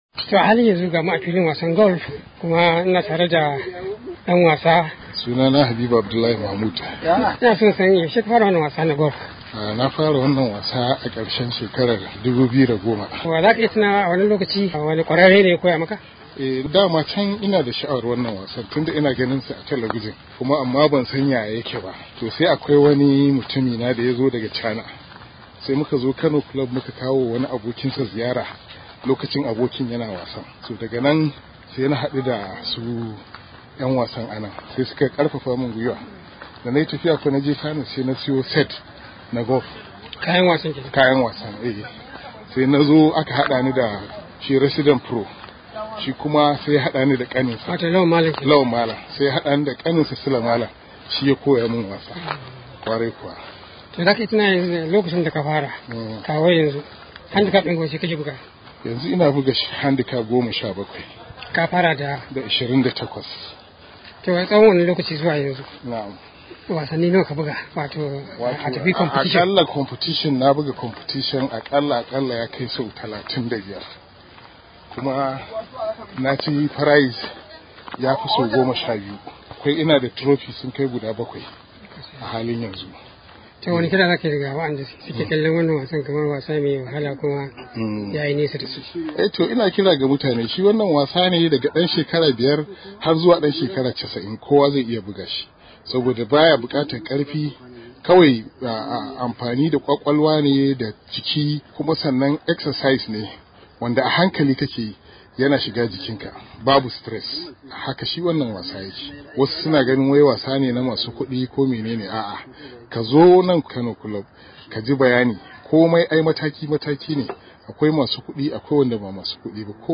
ya halarci filin wasan Golf na Kano Club ga rahoton sa.